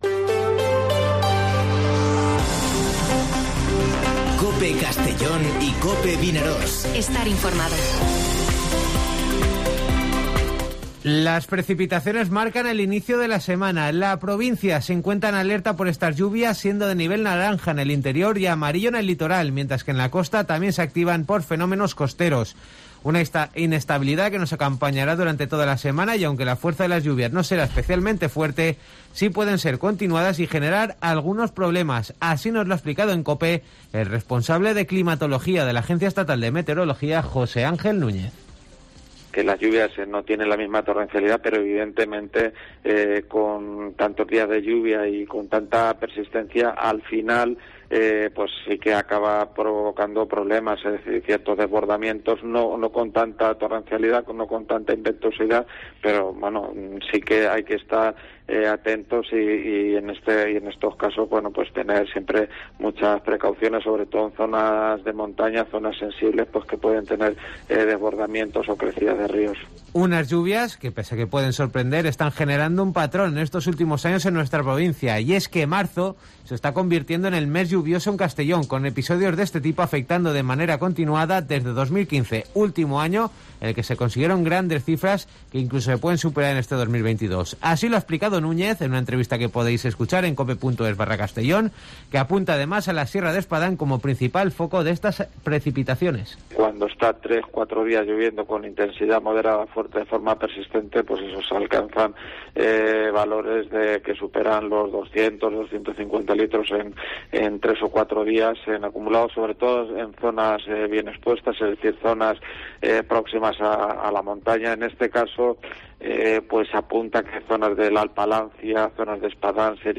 Informativo Mediodía COPE en Castellón (21/03/2022)